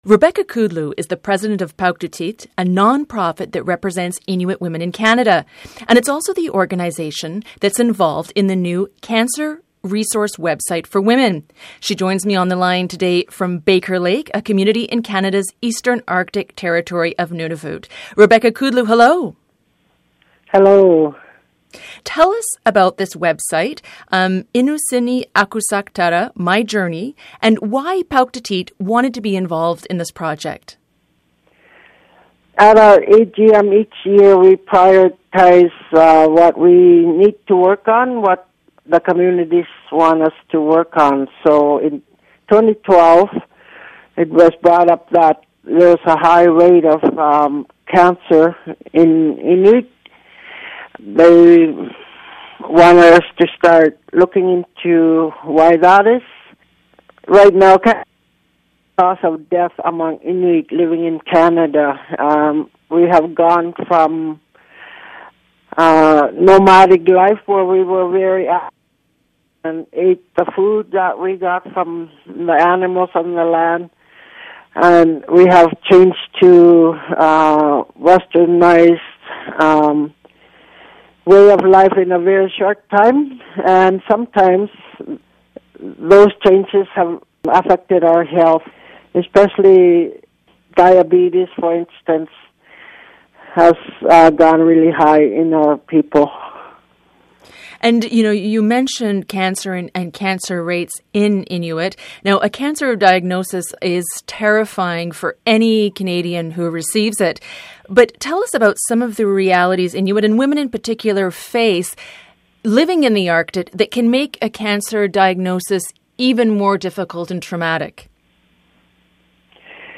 in a telephone interview this week from the community of Baker Lake in Canada’s eastern Arctic territory of Nunavut.